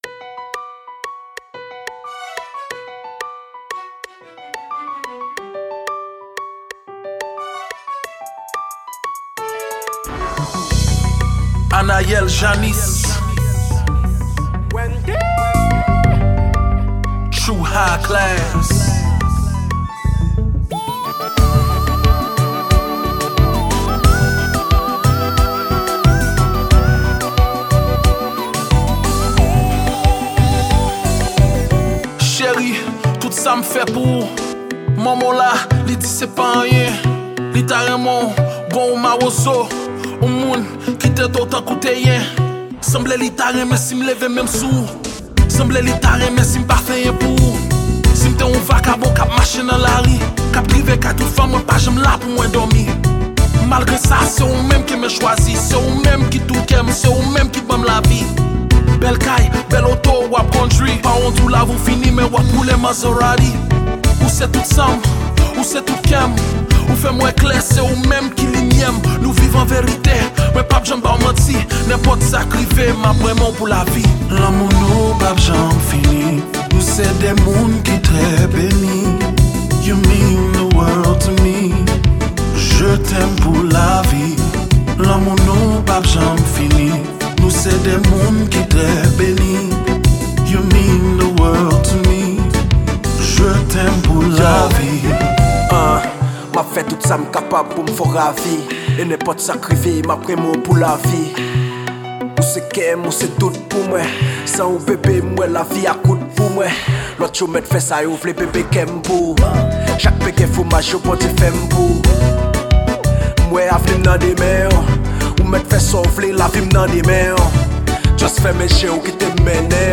Genre: Rap-Compas.